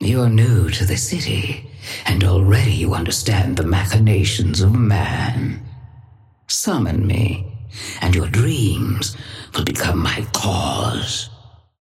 Patron_female_ally_viscous_start_02.mp3